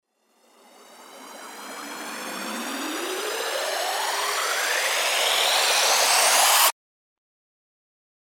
FX-1076-RISER
FX-1076-RISER.mp3